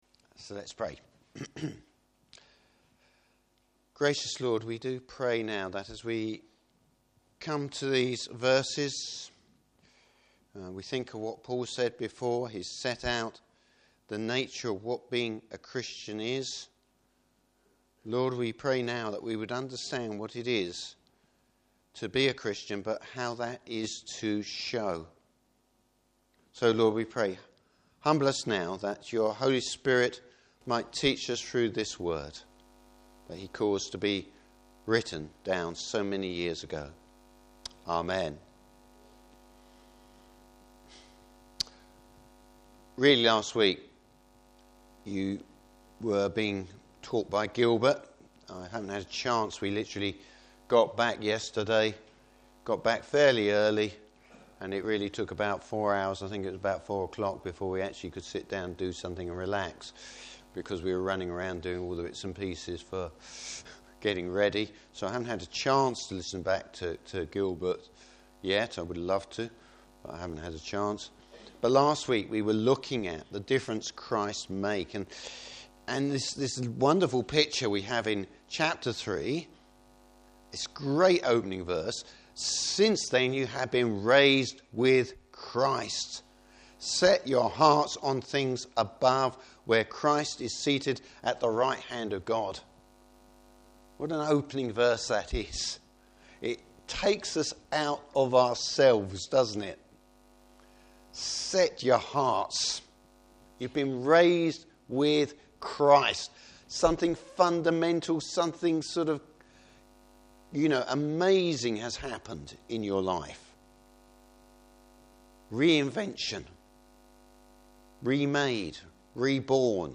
Service Type: Morning Service What should be the visible difference if you are a Christian?